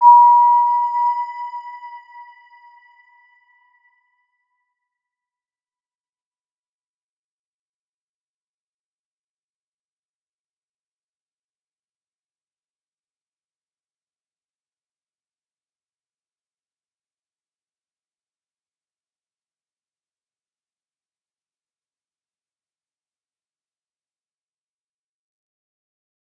Round-Bell-B5-mf.wav